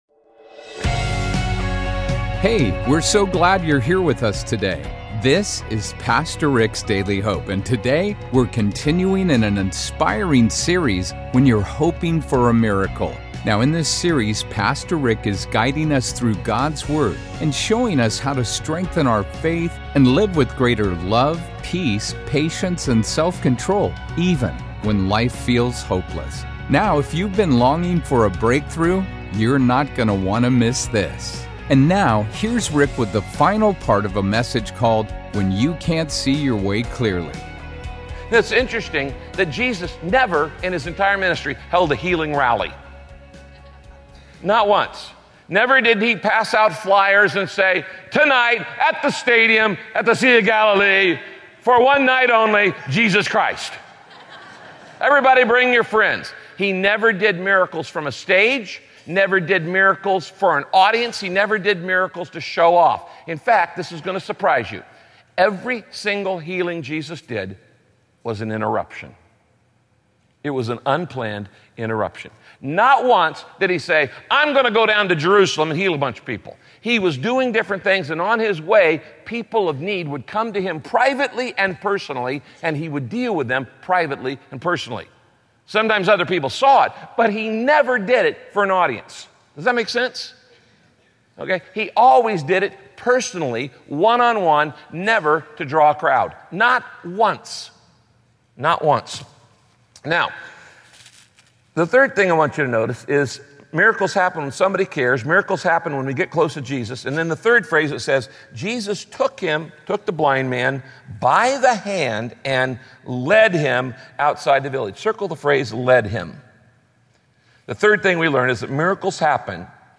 In this broadcast, Pastor Rick encourages you to focus on preparing for eternity instead of stressing over short-term problems.